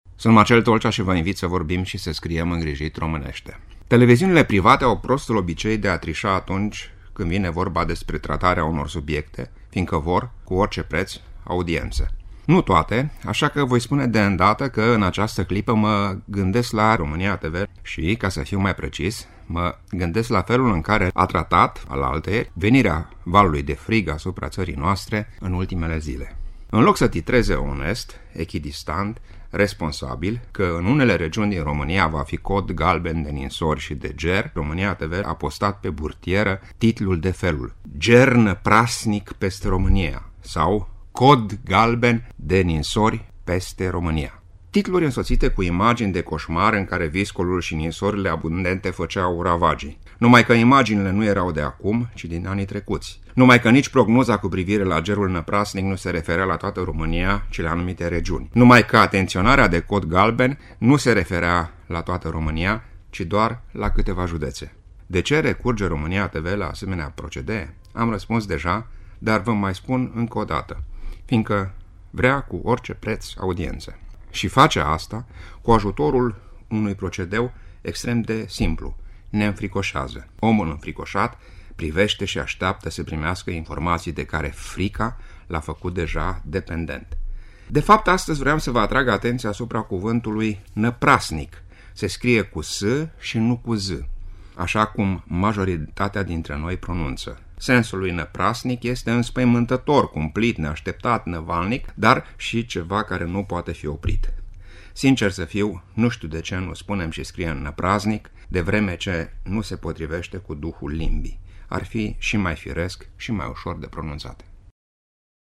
(rubrică difuzată în 5 ianuarie 2016)